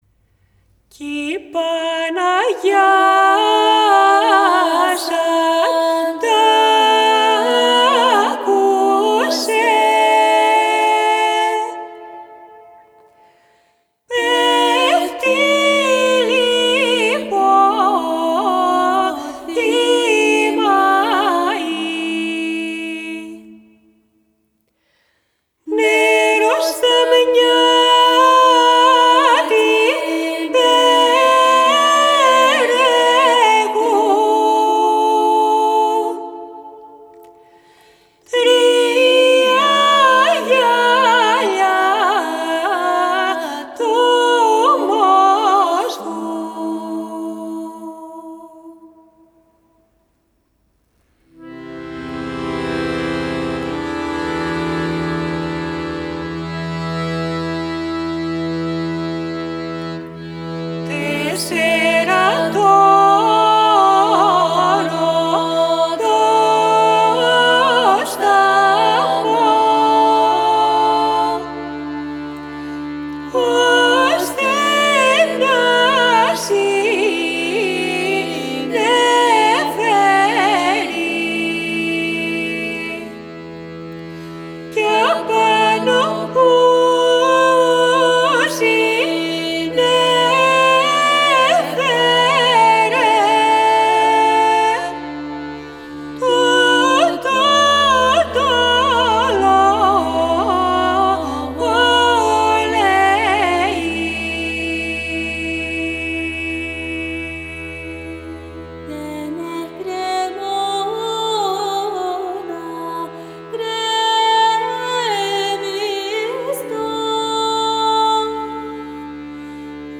Genre: World, Balkan Music